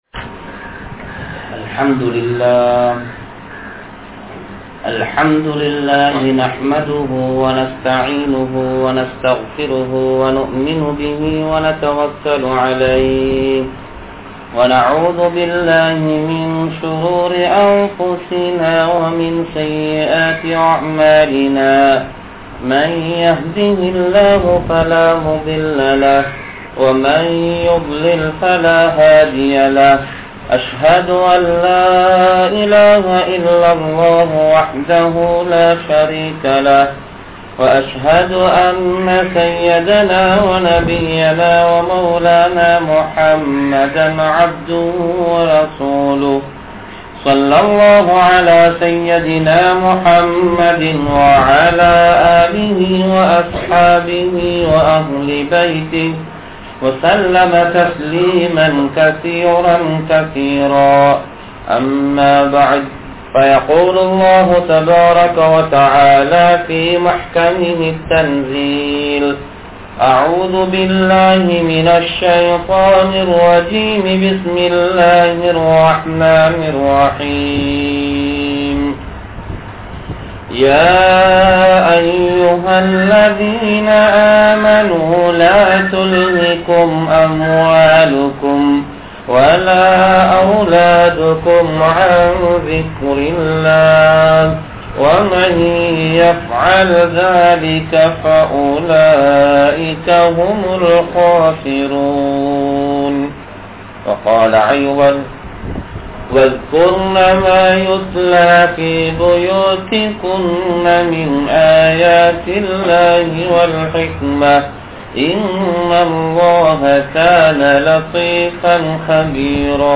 Al Quranai Sumakkum Ullangal (அல்குர்ஆனை சுமக்கும் உள்ளங்கள்) | Audio Bayans | All Ceylon Muslim Youth Community | Addalaichenai